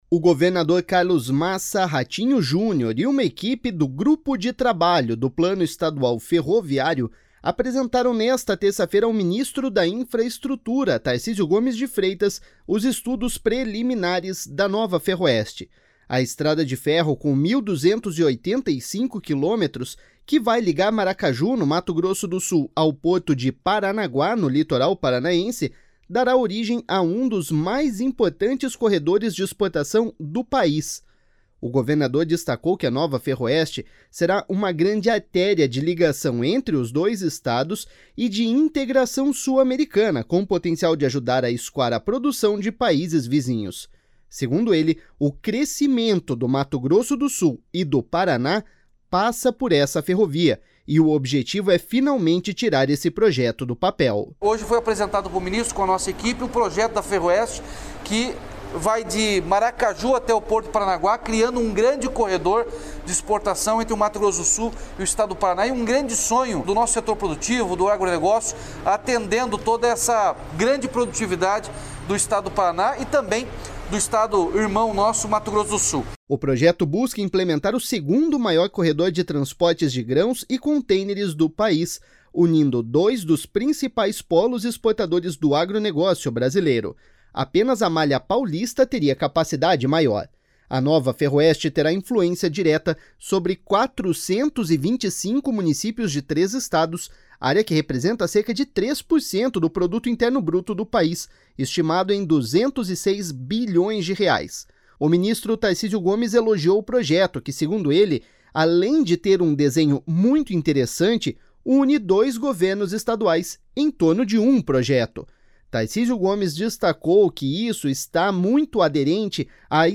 Segundo ele, o crescimento do Mato Grosso do Sul e do Paraná passa por essa ferrovia, e o objetivo é finalmente tirar esse projeto do papel.// SONORA RATINHO JUNIOR.//
Tarcísio Gomes destacou que isso está muito aderente à estratégia do Ministério da Infraestrutura de promover o modal ferroviário, que vem se tornando uma tendência.// SONORA TARCÍSIO GOMES.//
O governador do Mato Grosso do Sul, Reinaldo Azambuja, também participou dos encontros e disse estar otimista com o avanço do projeto.// SONORA REINALDO AZAMBUJA.//